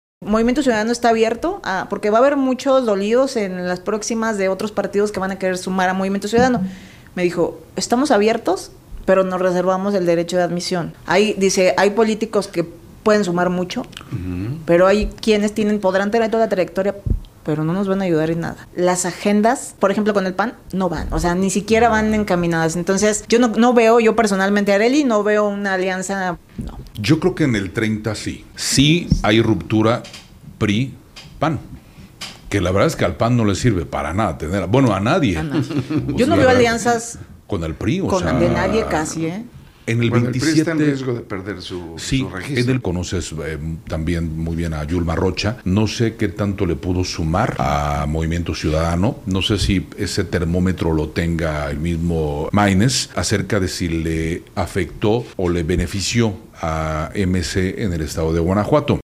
En Entrevista Que Le Generó Sound Effects Free Download